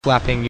flapping2
Category: Animals/Nature   Right: Personal